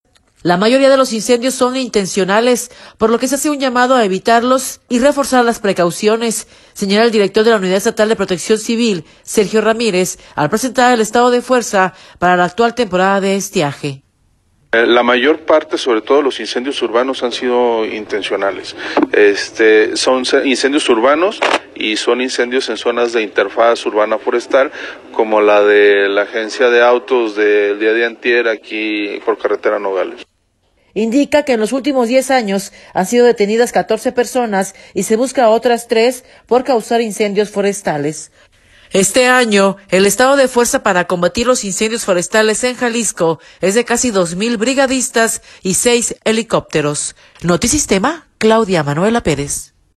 audio La mayoría de los incendios son intencionales, por lo que se hace un llamado a evitarlos y reforzar las precauciones, señala el director de la Unidad Estatal de Protección Civil, Sergio Ramírez, al presentar el estado de fuerza para la actual temporada de estiaje.